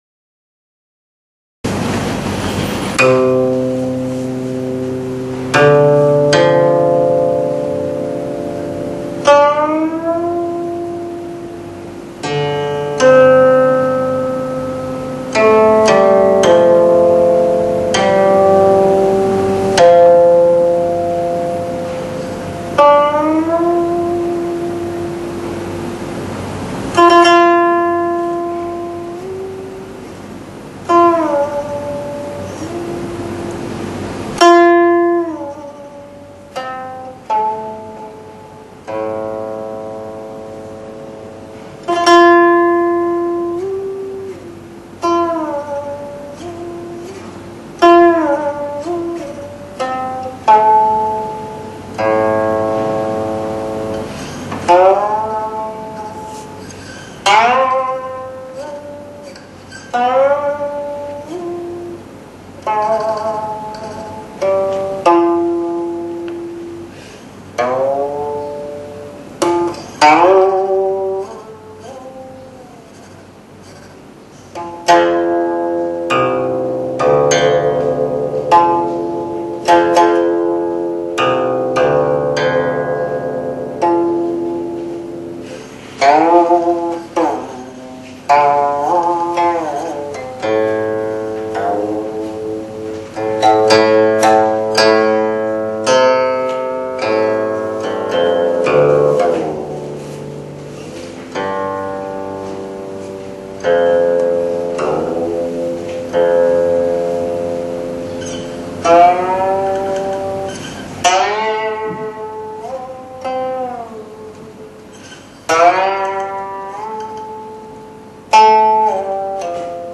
古琴教学示范《良宵引》